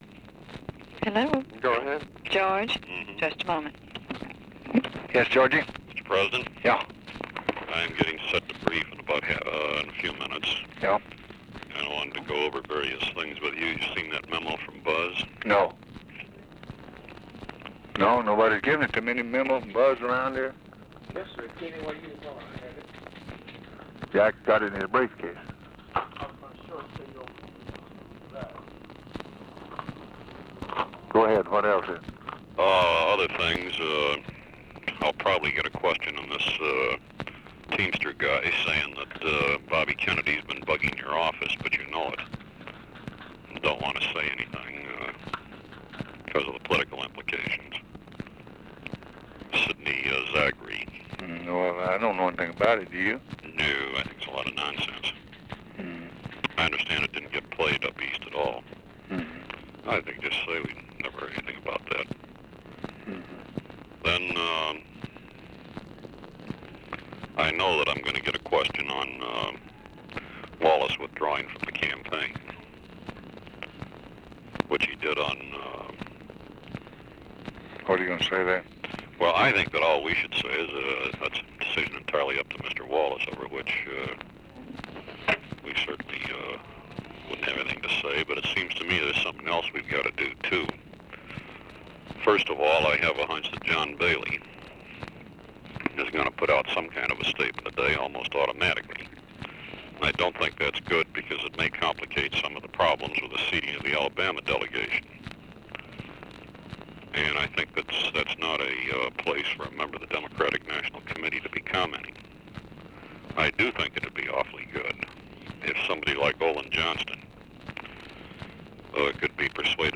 Conversation with GEORGE REEDY and OFFICE CONVERSATION, July 19, 1964
Secret White House Tapes